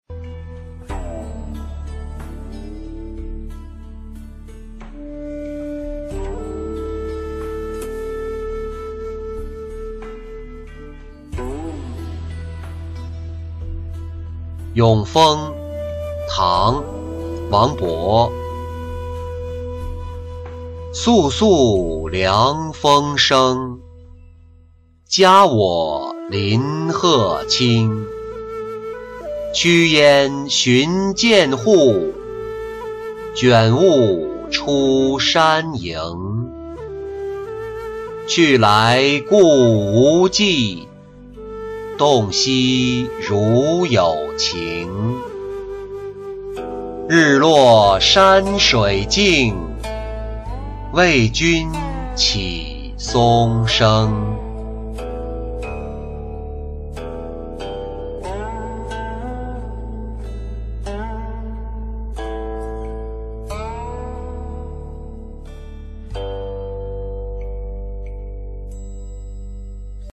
咏风-音频朗读